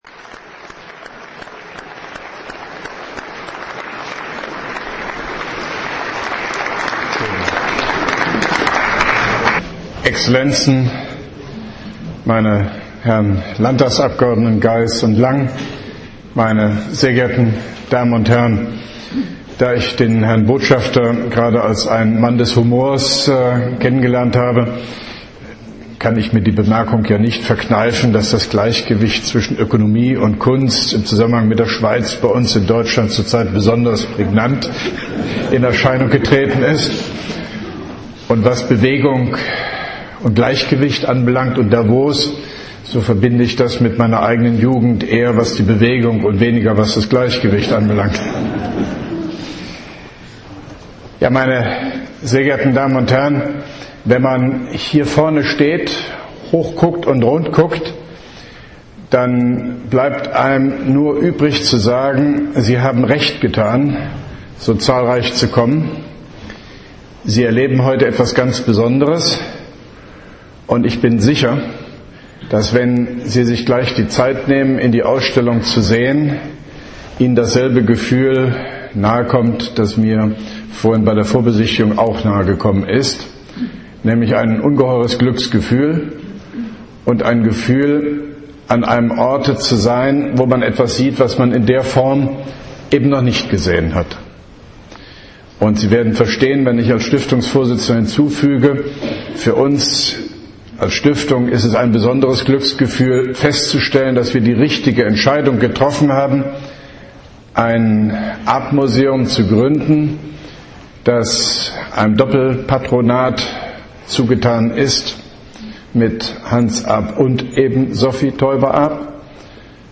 Ausstellungseröffnung Sophie Taeuber-Arp